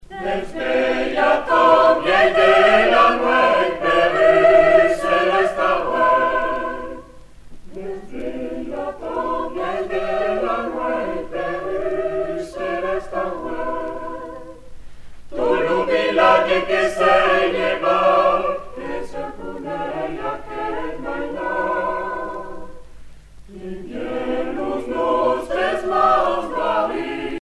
circonstance : Noël, Nativité
Genre strophique
Pièce musicale éditée